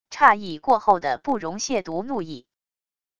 诧异过后的不容亵渎怒意wav音频